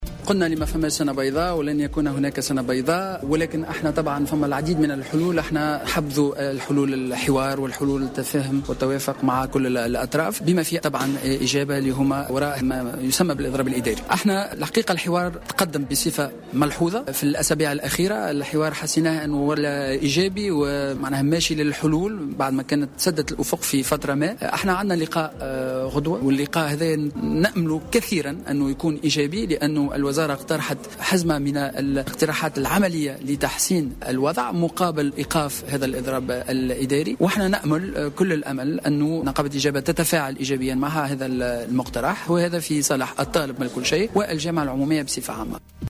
وأكد في تصريح اليوم لمراسل "الجوهرة أف أم" على هامش جلسة عامة في البرلمان، أنه لن يكون هناك سنة بيضاء، مشيرا إلى وجود بوادر انفراج.